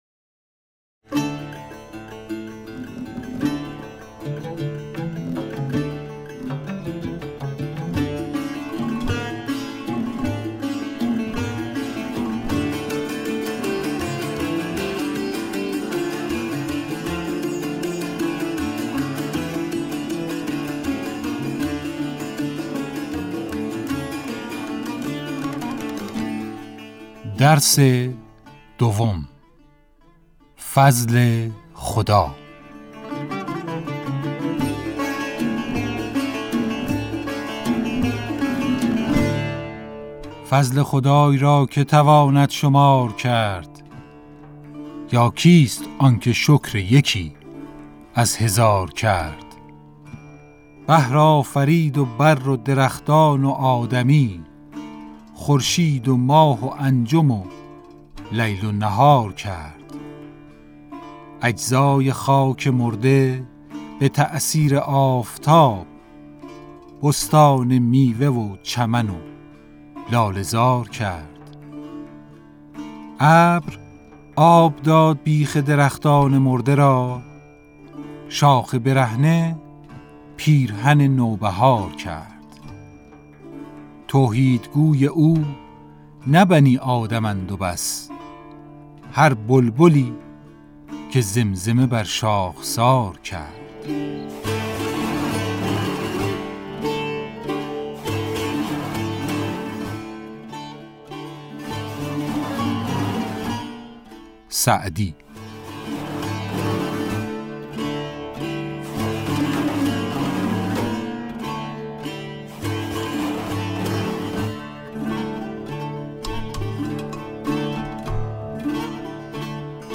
فایل صوتی شعر فضل خدا